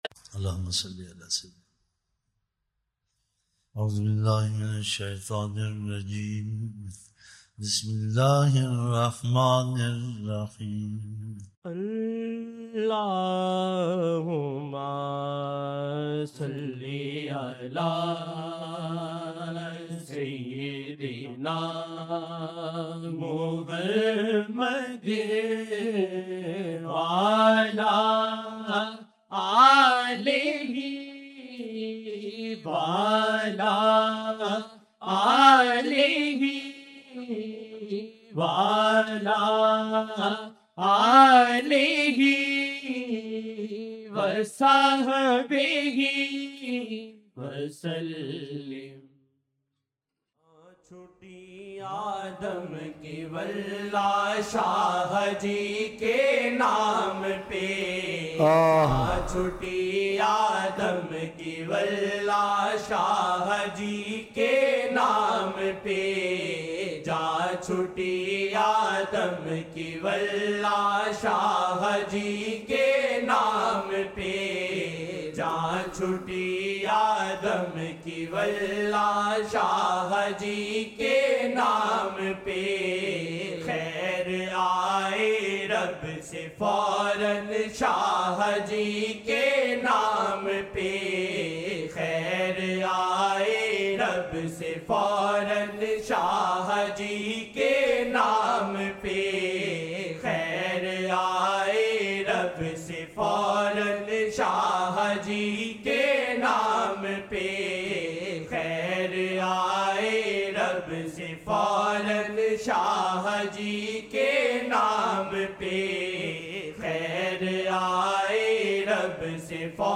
25-March-2011 Mehfil Part-1
Naat Shareef: Khair aaye Rab se foran Shaha ﷺ ji ke naam pe